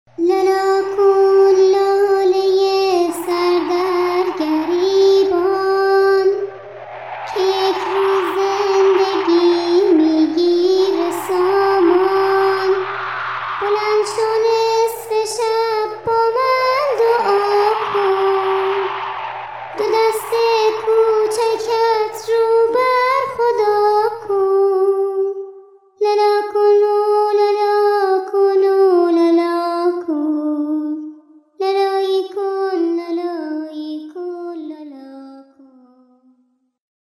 لالایی کودکانه